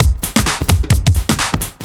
OTG_TripSwingMixB_130a.wav